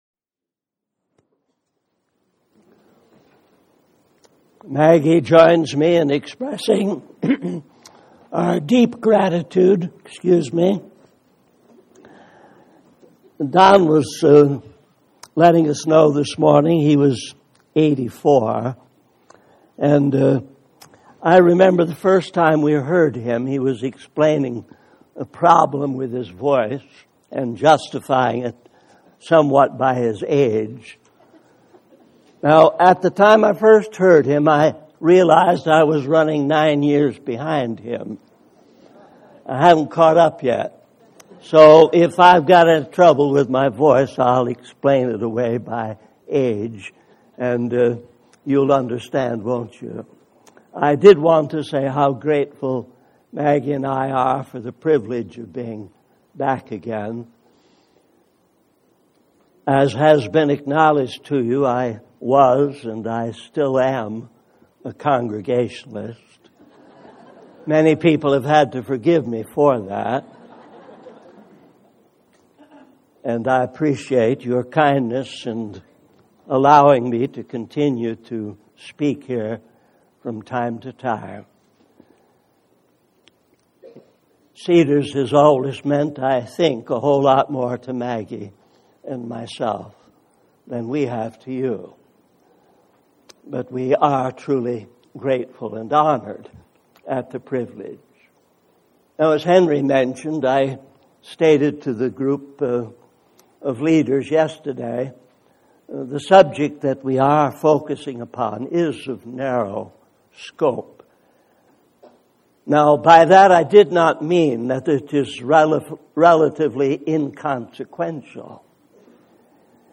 In this sermon, the pastor shares a story about a guest preacher who was asked to give a short sermon but ended up speaking for a long time.